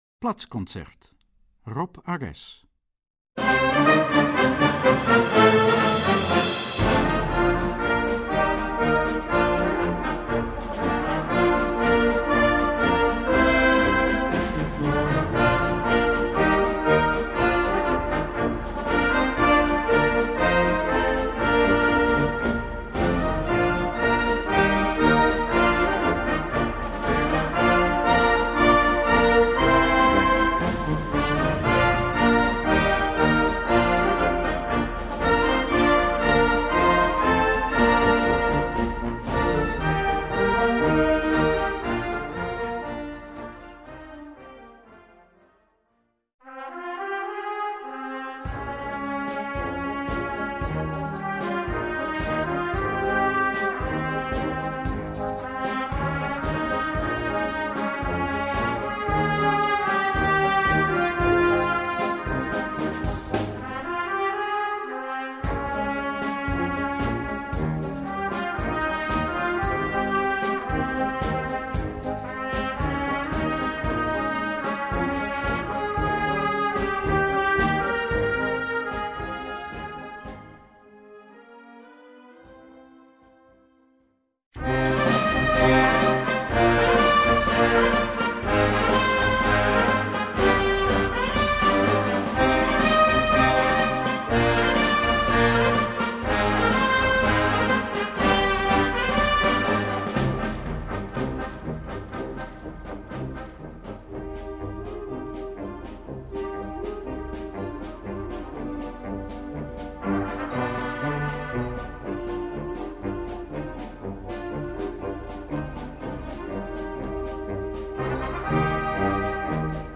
Gattung: Platzkonzert
Besetzung: Blasorchester